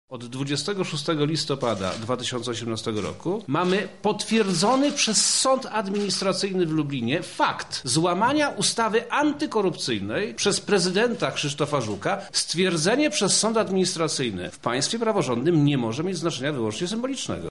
Sytuacja wydaje się już jasna – mówi Wojewoda Lubelski Przemysław Czarnek.